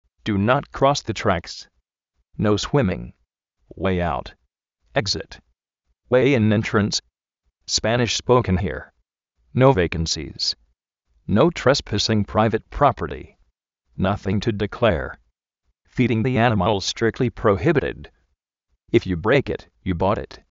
du not kros de traks
nóu suímin
uéi áut, éksit
spánish spóukn jí:r
názin tu dikléar